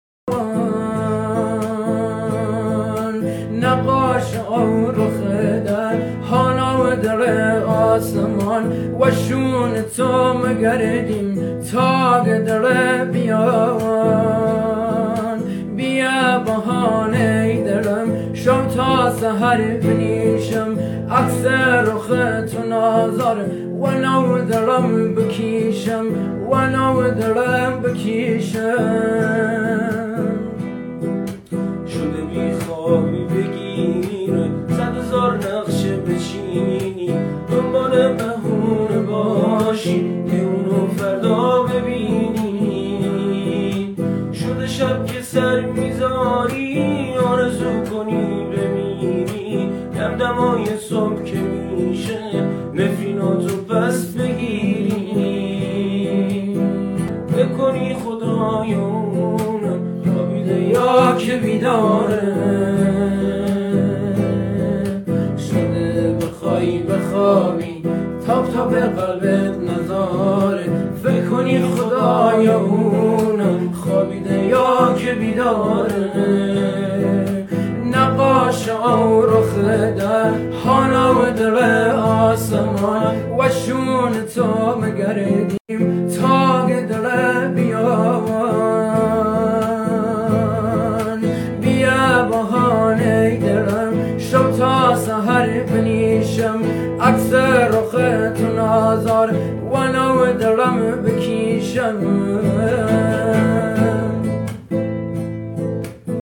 دانلود آهنگ کردی